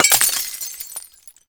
glass_fall02hl.ogg